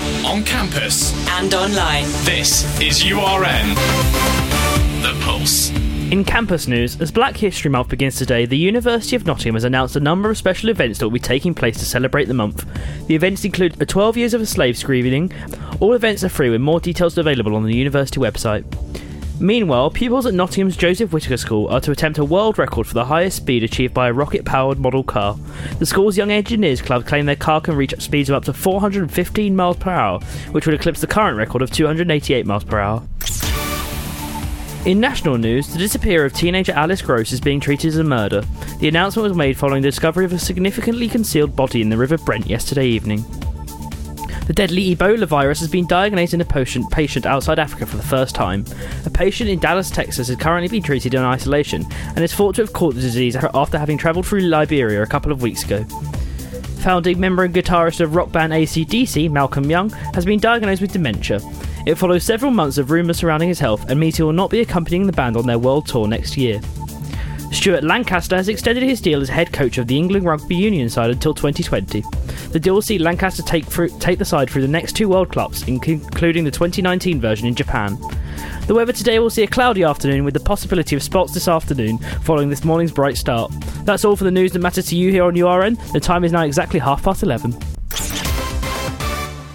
Listen to all of today's news, as read live on URN